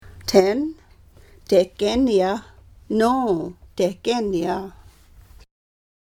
Reading Indigenous Translations of Riel: Heart of the North -- Audio Recordings